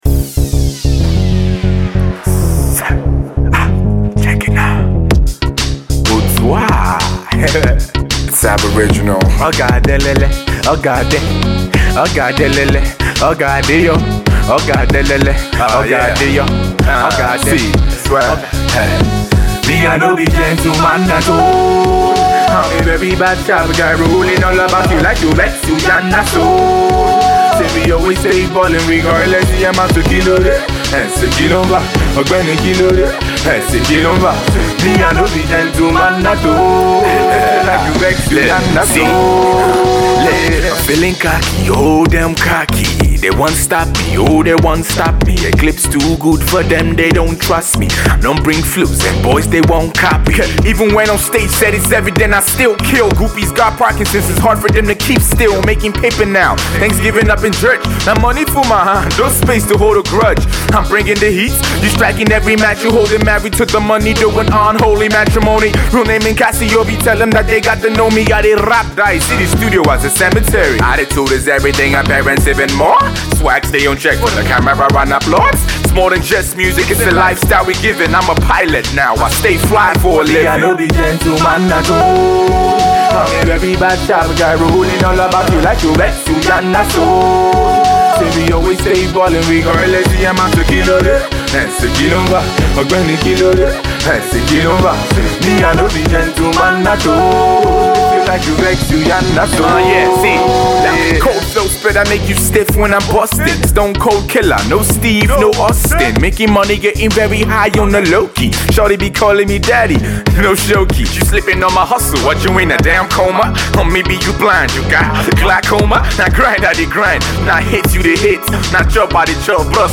Rap
hip hop